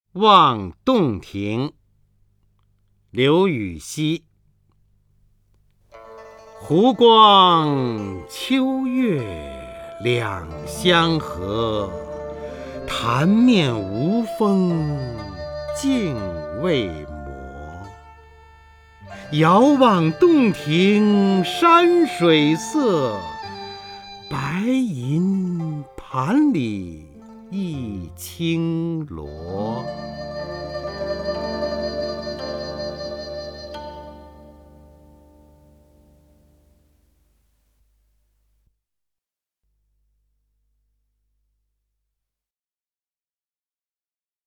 方明朗诵：《望洞庭》(（唐）刘禹锡) （唐）刘禹锡 名家朗诵欣赏方明 语文PLUS